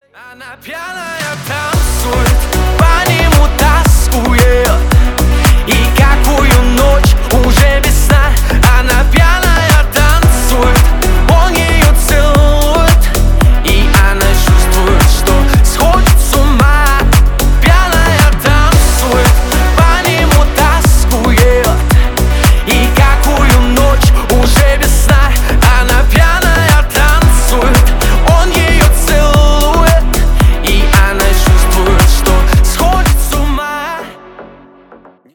• Качество: 320, Stereo
поп
клубные
Русский клубняк 2023